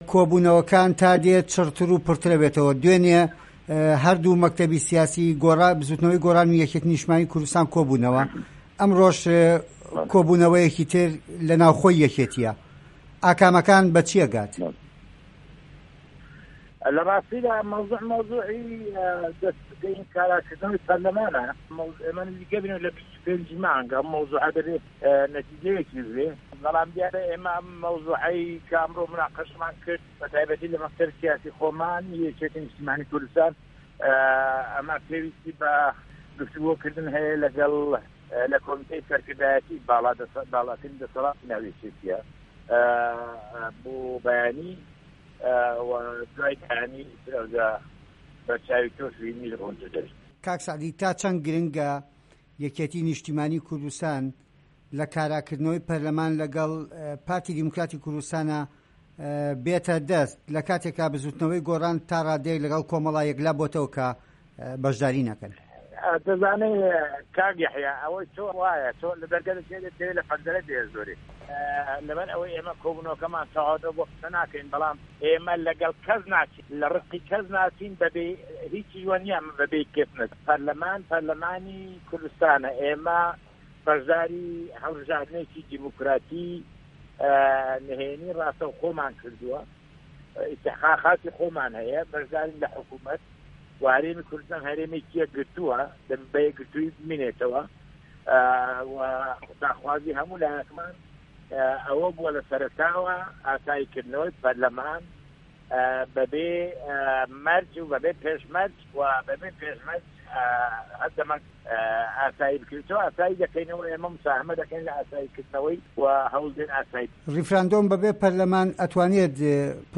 بەڵام دەنگی ئەمریکا ئەم وتوێژەی لەگەڵ ئه‌ندامی مەکتەبی سیاسی یەکێتی نیشتیمانی سەعدی ئەحمەد پیرە سازداوە.
وتووێژی کاک سه‌عدی ئه‌حمه‌د پیره‌